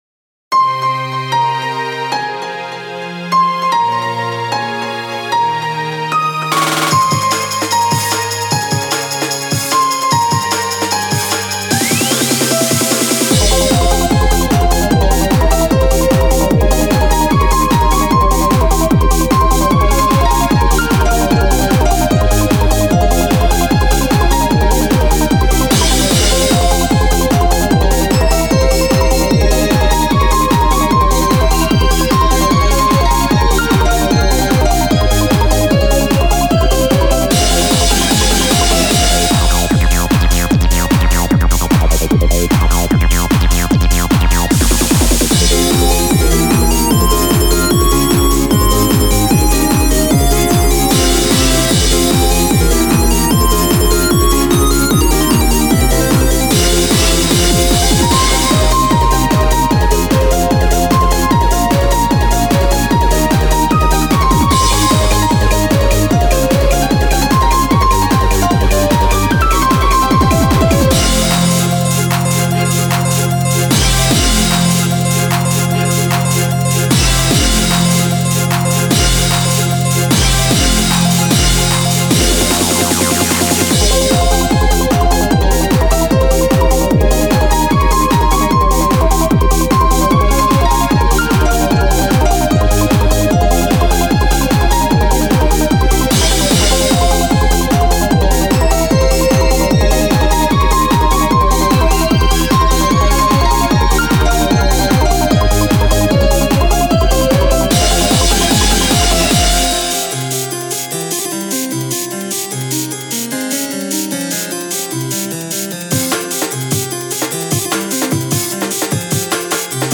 テクノ